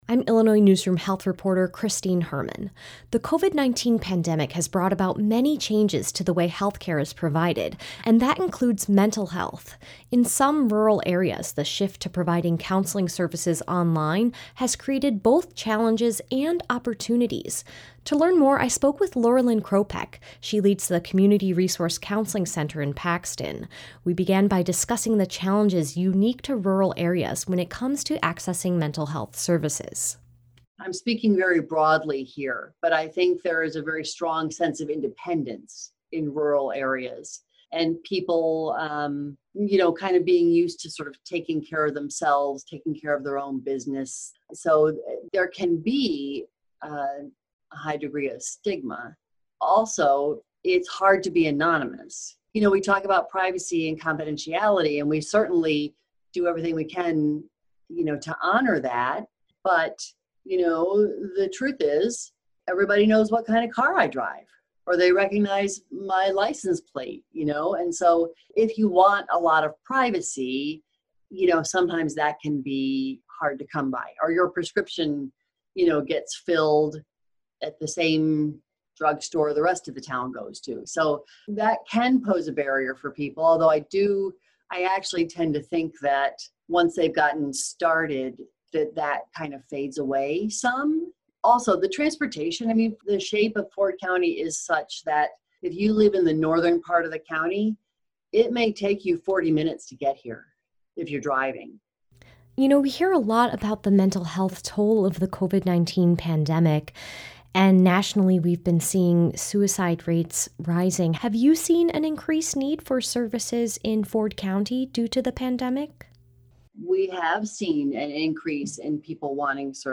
This interview has been lightly edited and condensed for length and clarity.